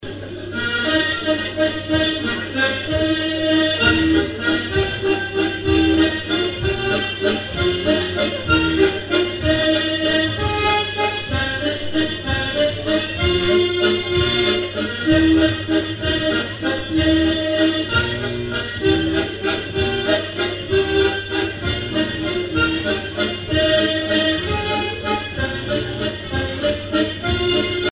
danse : valse
airs pour animer un bal
Pièce musicale inédite